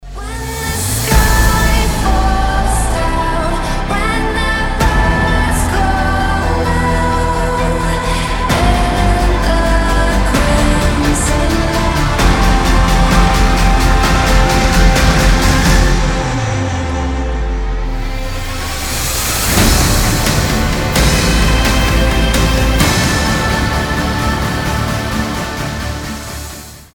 • Качество: 320, Stereo
громкие
эпичные